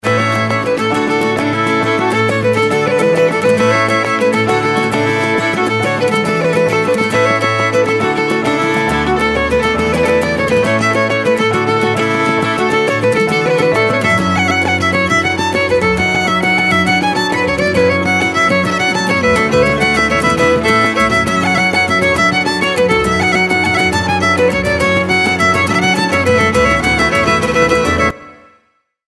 Outstanding jig